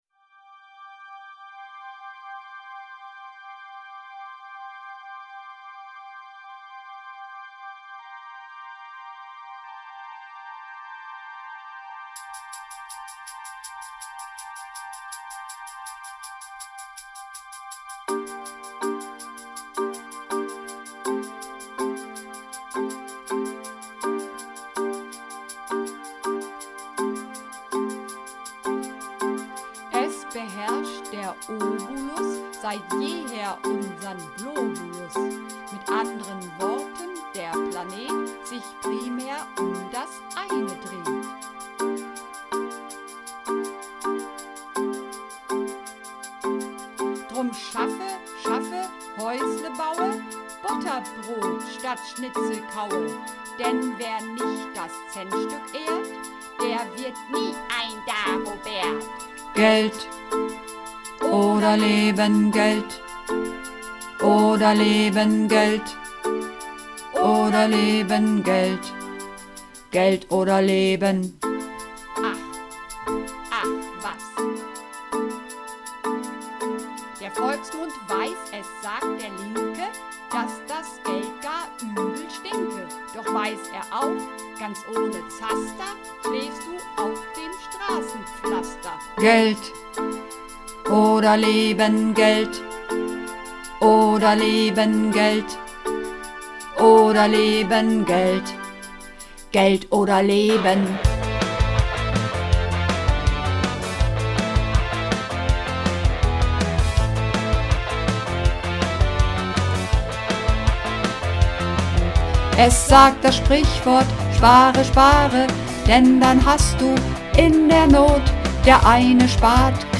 Übungsaufnahmen - Geld oder Leben
Runterladen (Mit rechter Maustaste anklicken, Menübefehl auswählen)   Geld oder Leben (Bass)
Geld_oder_Leben__2_Bass.mp3